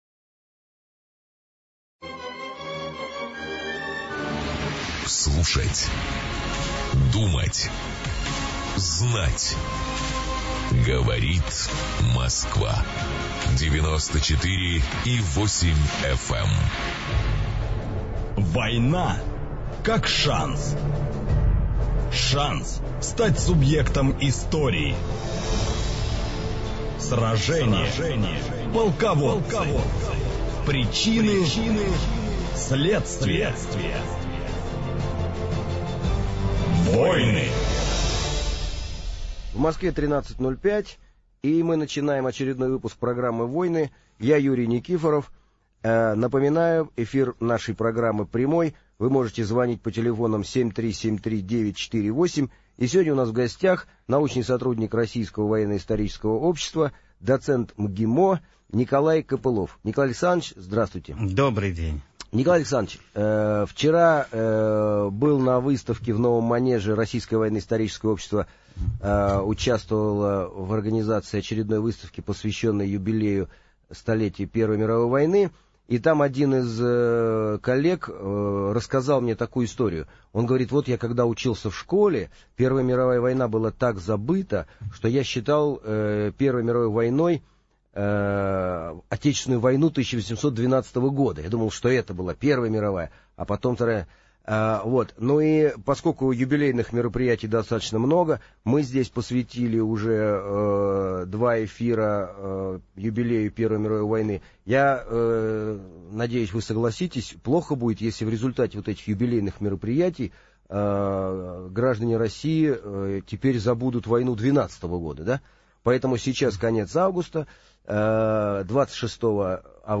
Аудиокнига Отечественная война 1812 года | Библиотека аудиокниг